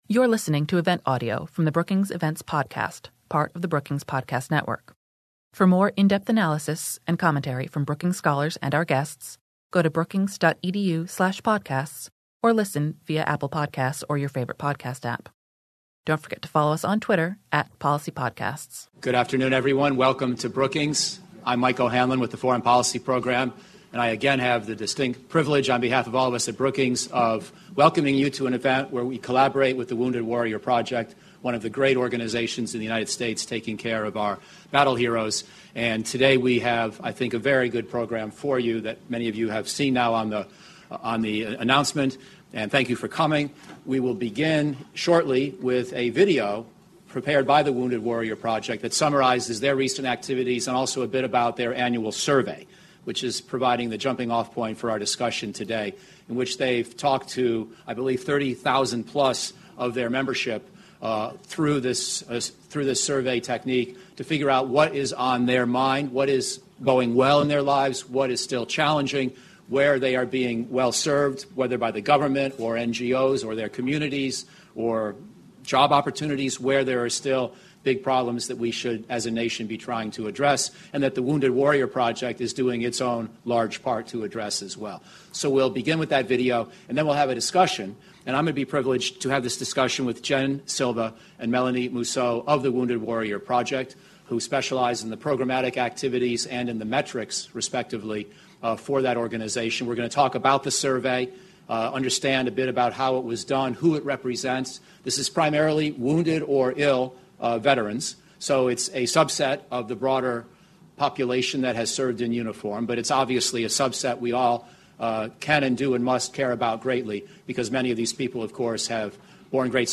On October 30, Wounded Warrior Project and the Brookings Institution hosted a panel discussion about the most pressing issues facing America’s wounded, ill, and injured servicemembers and veterans.
Keynote address by Dr. Paul R. Lawrence